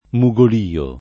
DOP: Dizionario di Ortografia e Pronunzia della lingua italiana
mugolio [